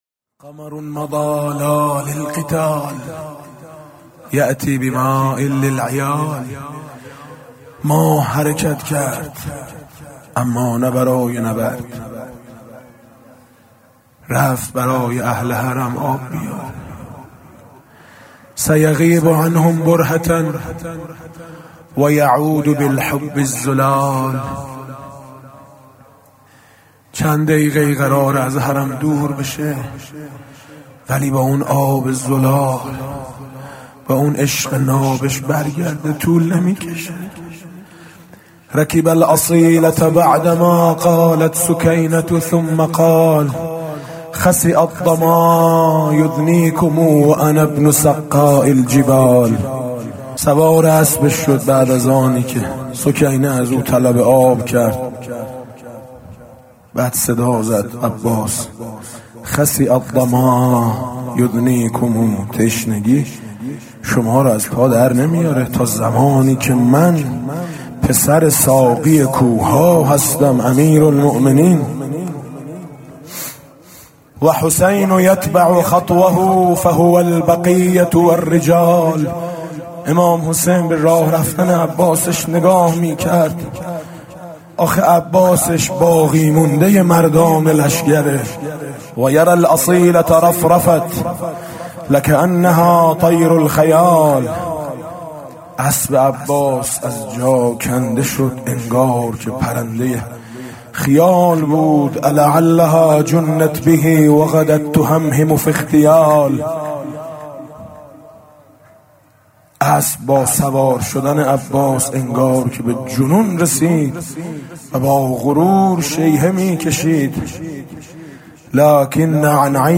محرم 99 - شب تاسوعا - روضه - مُتْ یا بُنَیَّ وَ لا تَعُدْ